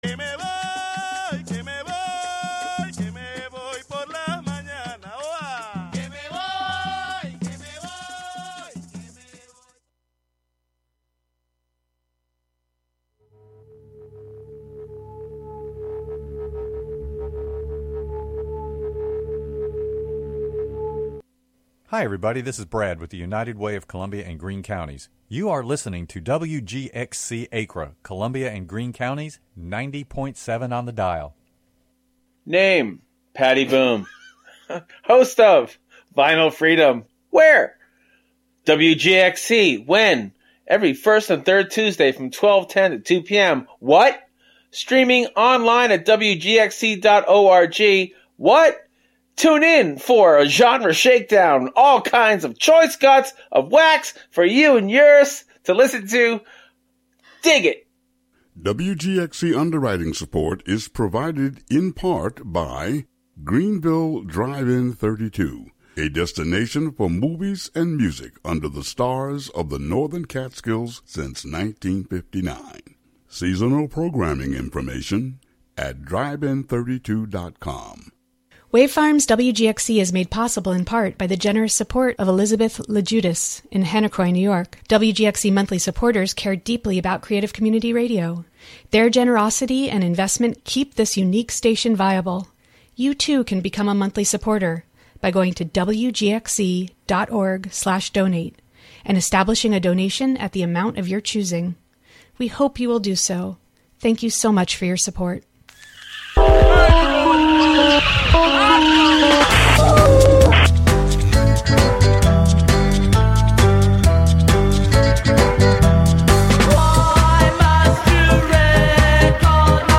broadcast live from WGXC's Hudson studio.